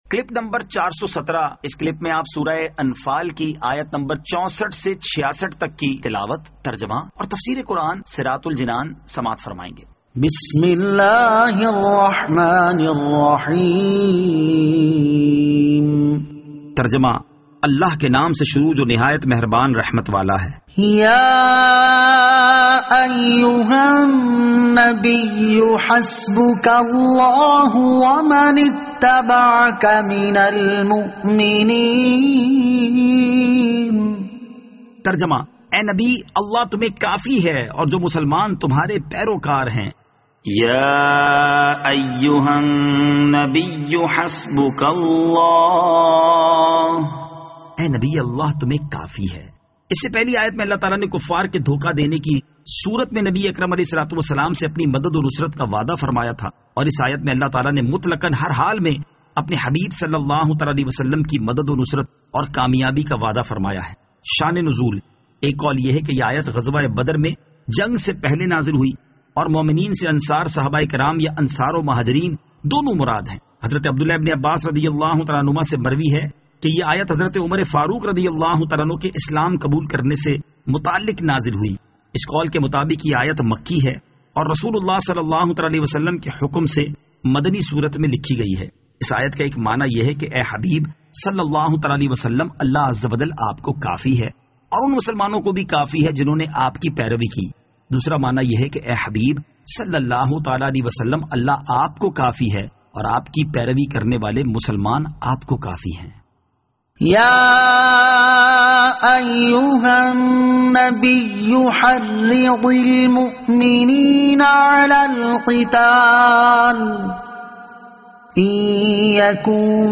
Surah Al-Anfal Ayat 64 To 66 Tilawat , Tarjama , Tafseer